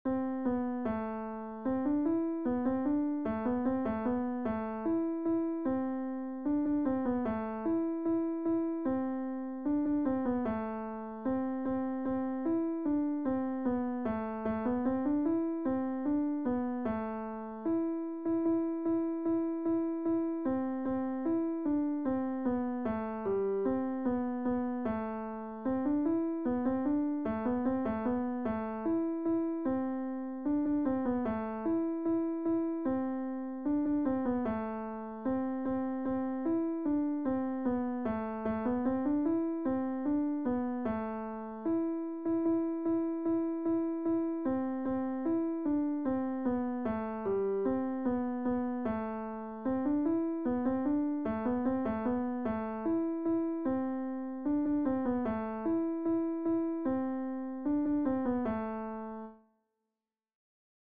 La, la, la je ne l'ose dire Tenor - Chorale Concordia 1850 Saverne